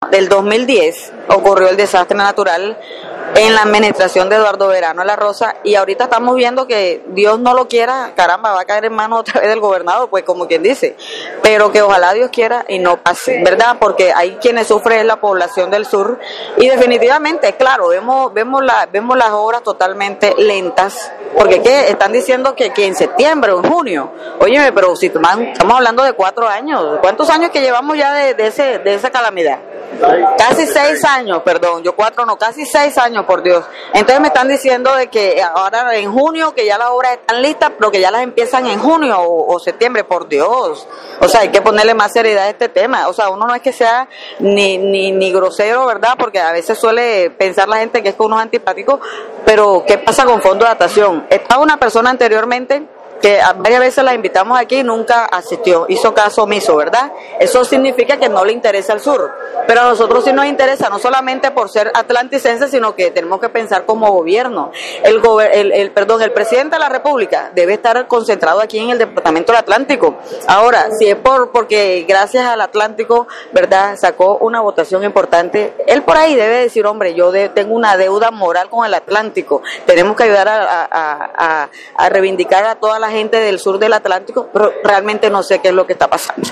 La diputada Merlys Miranda advirtió que hay que ponerle seriedad al tema de las obras para el sur del departamento del Atlántico.